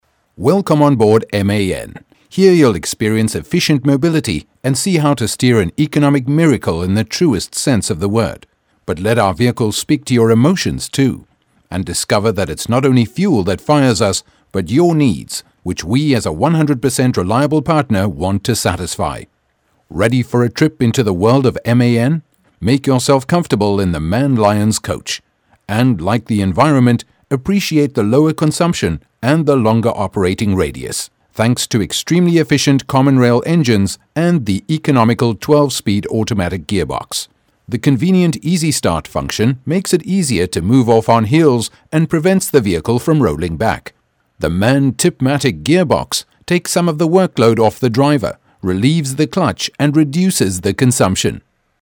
Rich, Distinctive, International, South African, Pan-African, Voice-over , Accents, Mid-Atlantic
Sprechprobe: Industrie (Muttersprache):